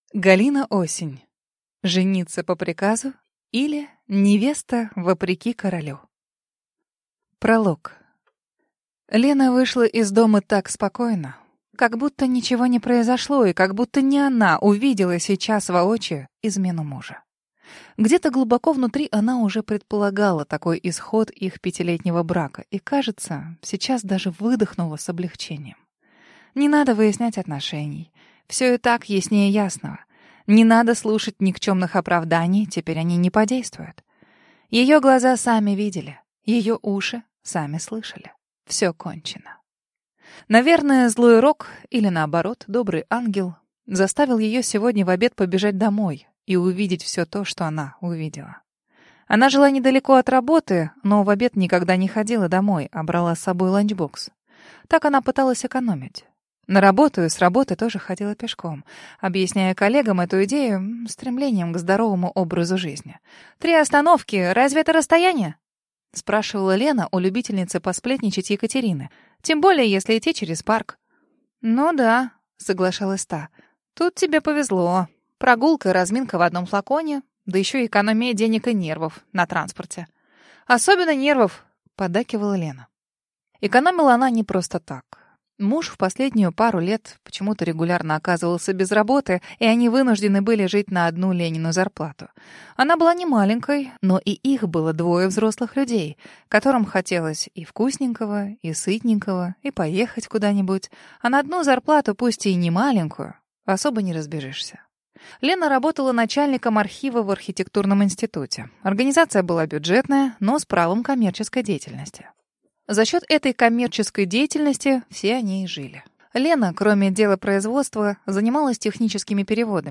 Аудиокнига Жениться по приказу, или Невеста вопреки королю | Библиотека аудиокниг